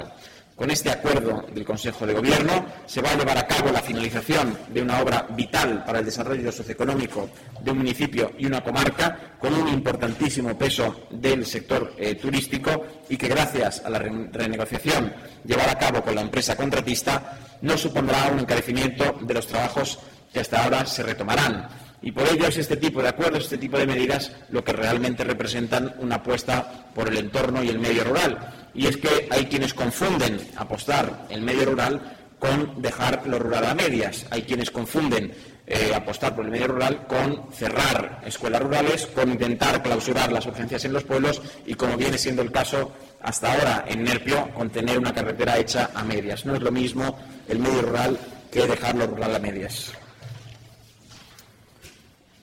El portavoz del Gobierno regional, Nacho Hernando, sobre carretera de Nerpio: